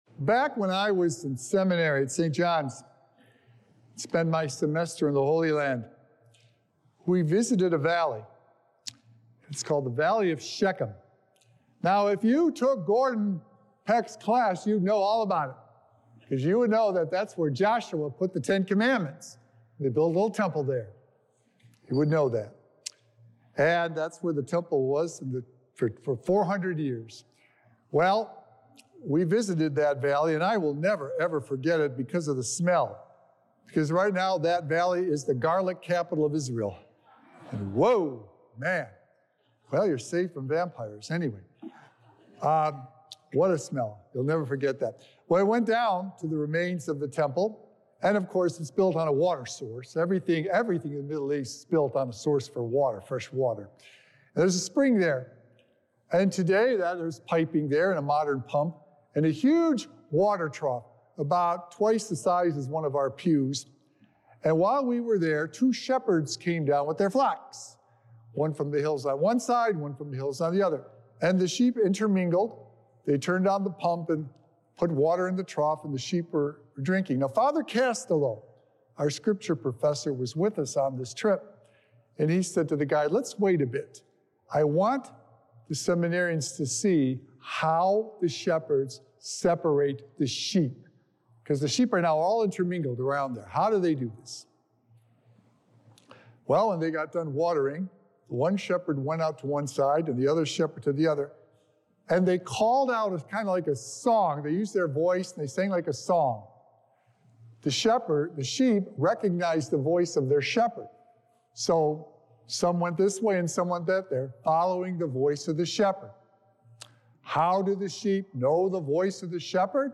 Sacred Echoes - Weekly Homilies Revealed